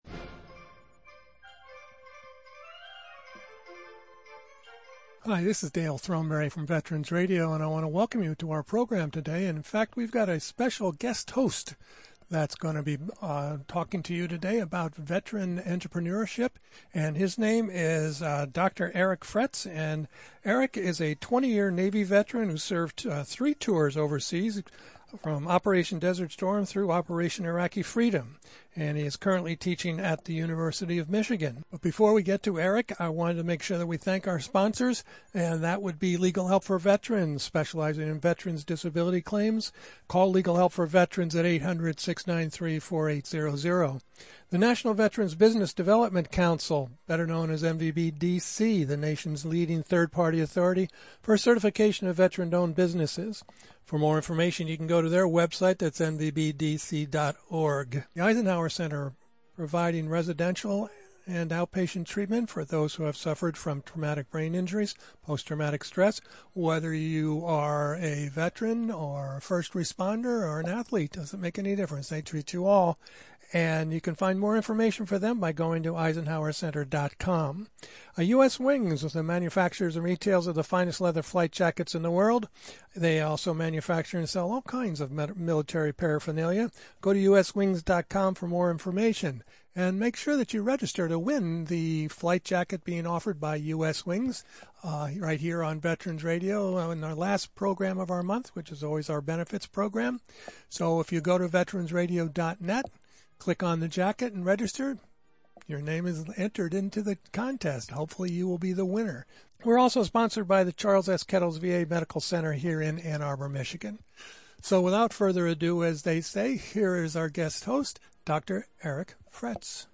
July 18, 2021 This week’s one hour radio broadcast is pre-recorded.